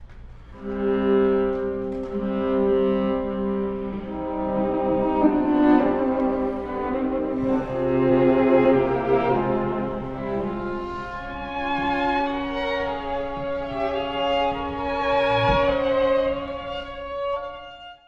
Andante
～歩くように～
ハ短調ではあるが、とても慈しみ深い音楽です。
8分の6拍子が、ややシチリアーノ風をかもしだしています。